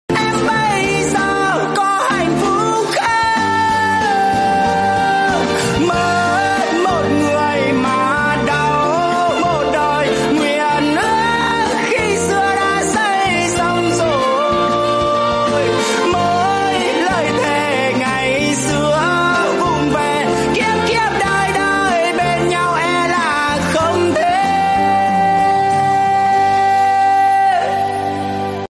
Nhạc Tiktok 58 lượt xem 18/02/2026
Giai điệu buồn, sâu lắng, chất lượng cao MP3/M4A/WAV.